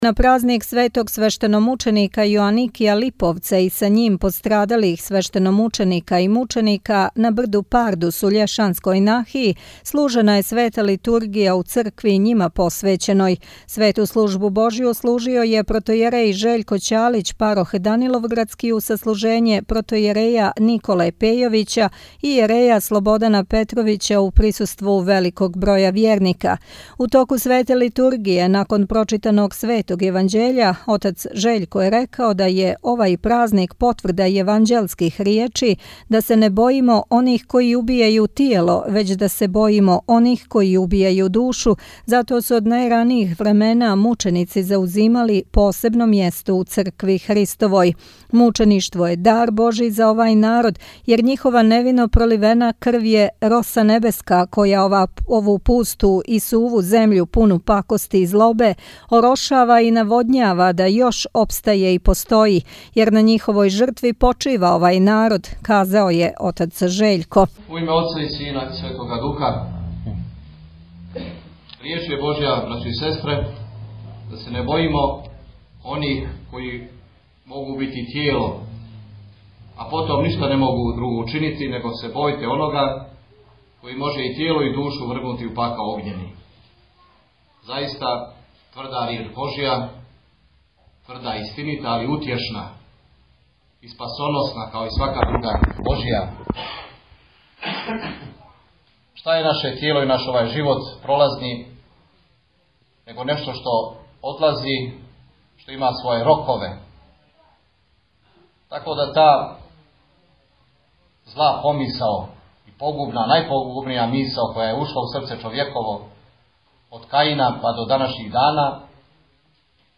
Бесједе
На празник Светог Свештеномученика Јоаникија Липовца и са њим пострадалих свештеномученика и мученика, на брду Пардус у Љешанској Нахији служена је Света Литургија у цркви њима посвећеној.